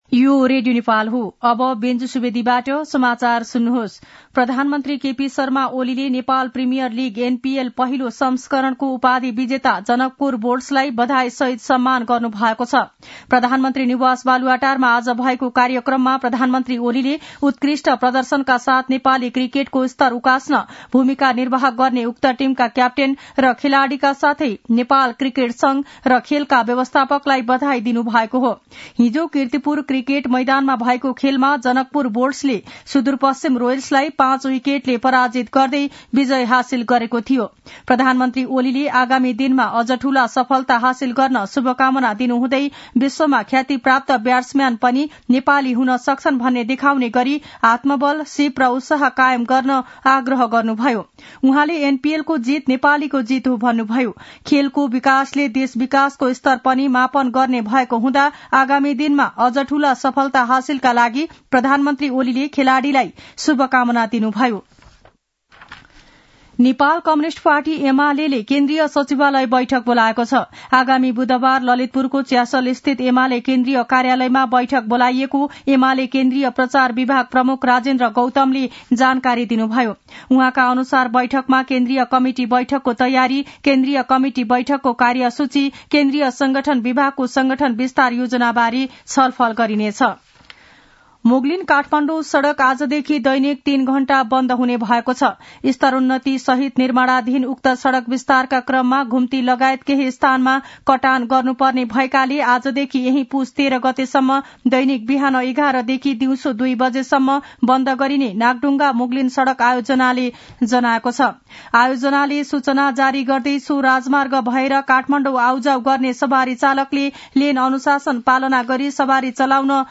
दिउँसो १ बजेको नेपाली समाचार : ८ पुष , २०८१
1-pm-nepali-news-1-16.mp3